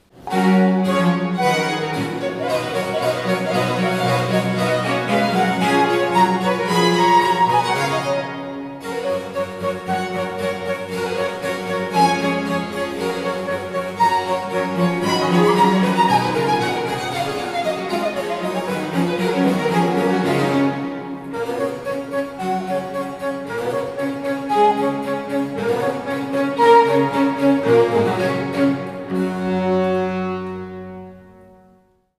flet traverso oraz zespołu muzyki dawnej Musicarion
skrzypce barokowe
altówka barokowa
wiolonczela barokowa
klawesyn.